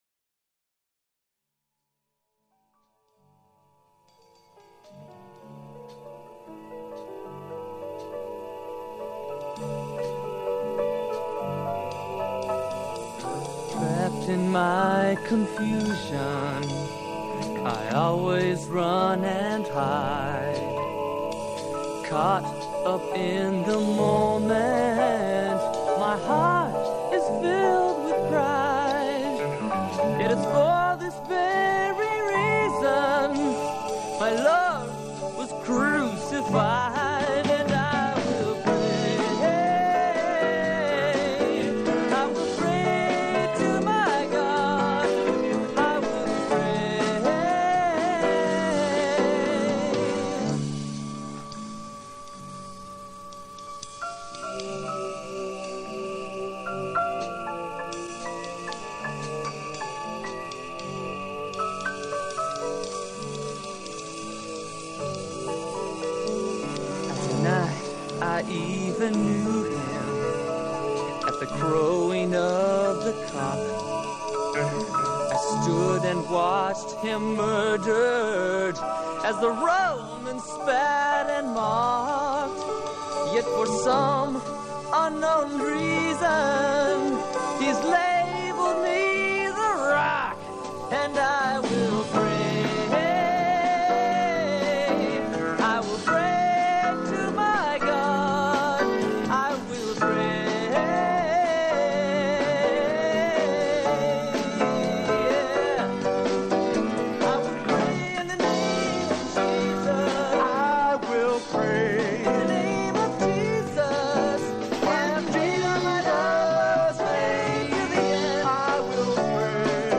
I digitized all the recordings from scratchy and warbly cassette tapes that sat in a box for decades.